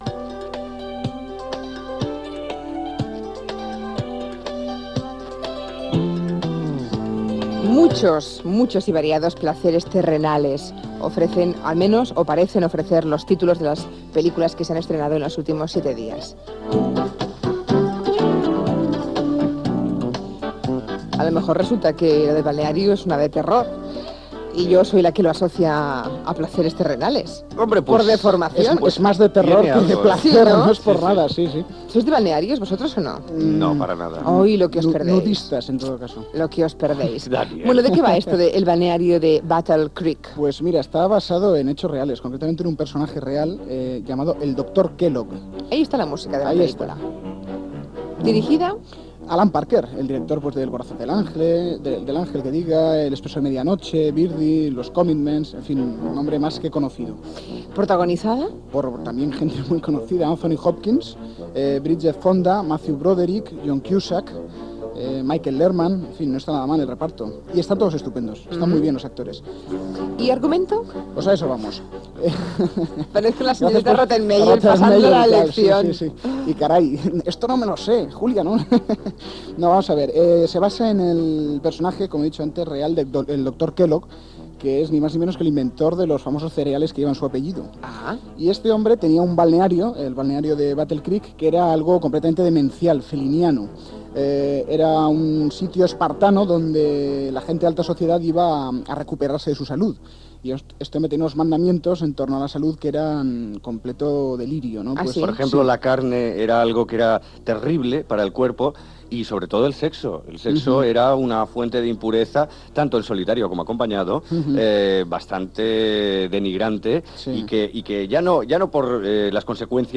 Secció de crítica cinematogràfica de la pel·lícula "El balneario de Battle Creek"
Info-entreteniment